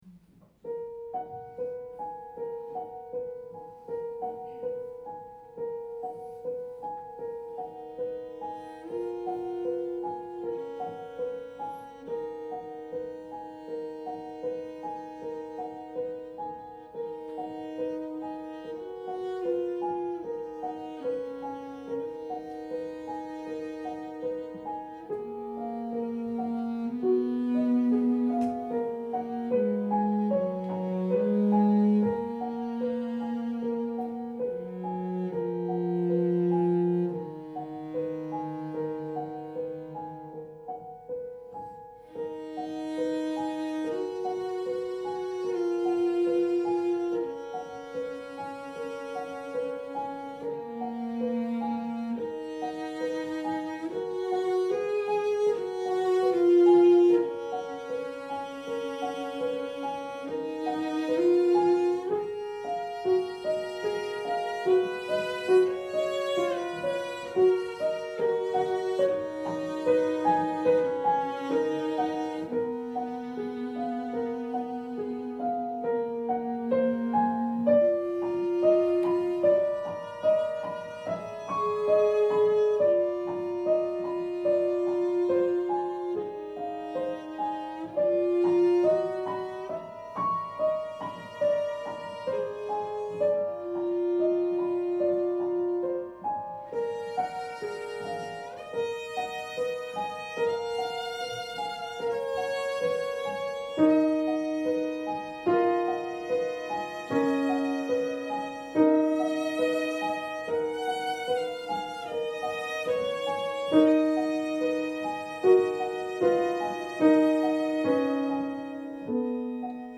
Mitgeschnitten bei einem Konzert im Franz Radziwill Haus in Dangast im Dezember 2018 mit dem dortigen historischen Flügel.